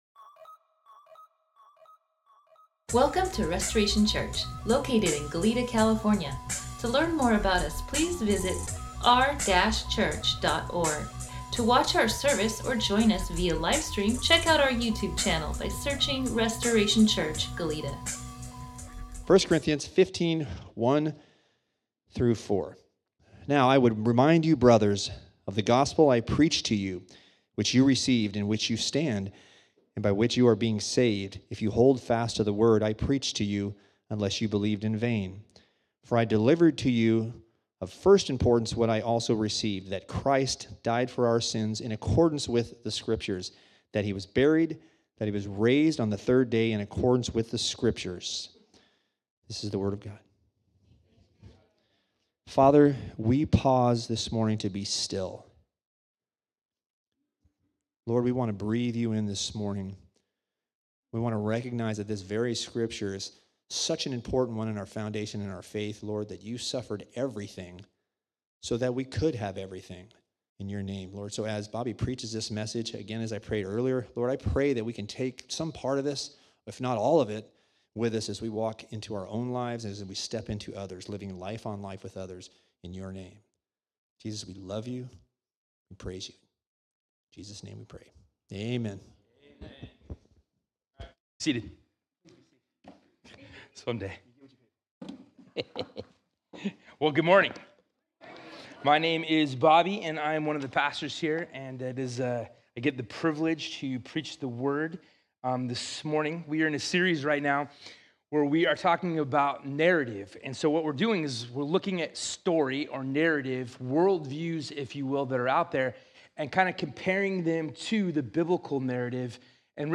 Sermon NotesDownload Welcome to Restoration Church, Goleta!